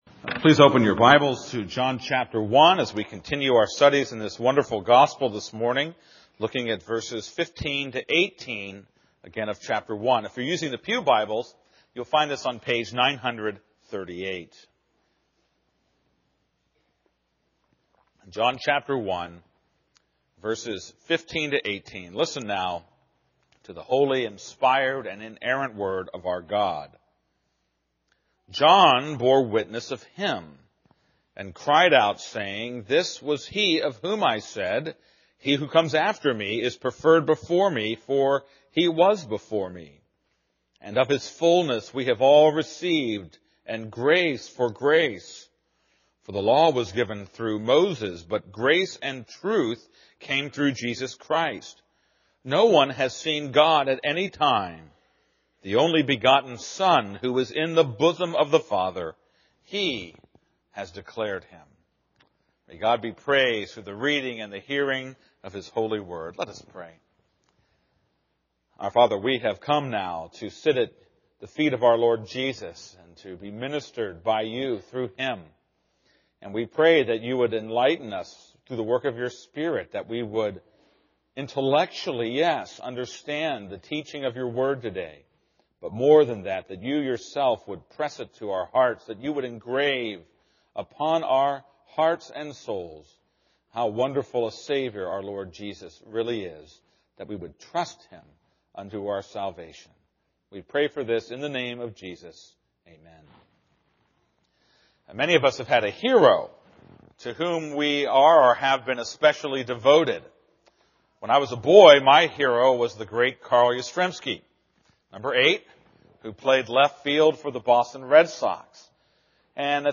This is a sermon on John 1:15-18.